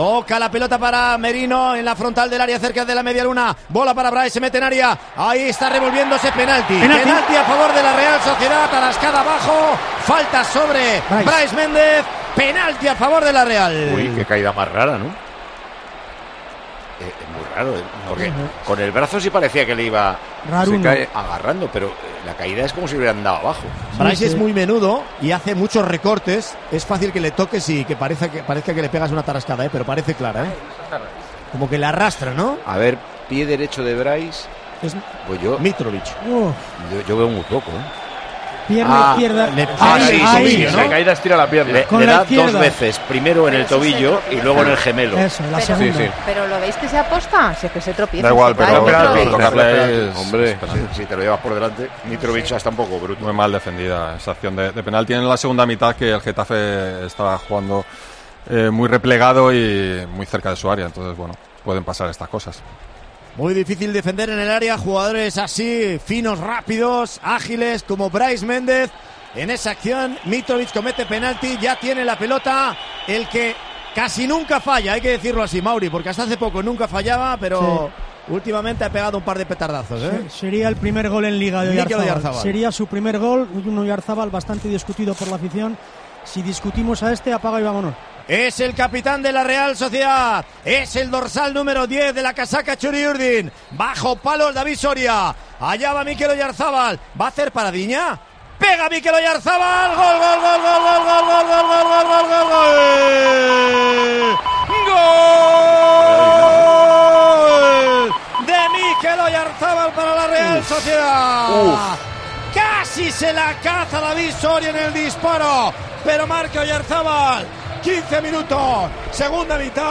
Micrófono de COPE en Anoeta Reale Arena